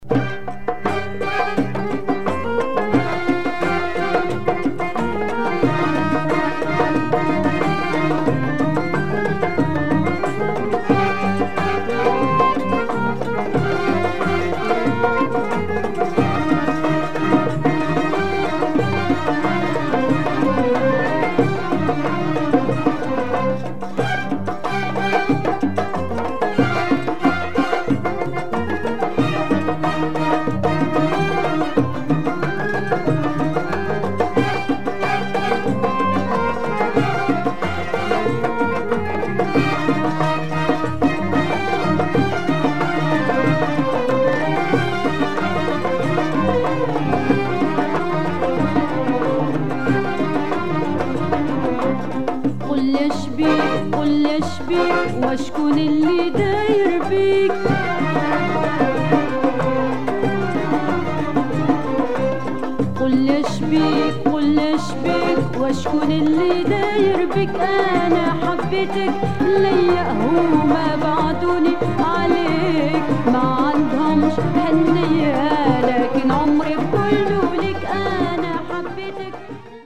female singer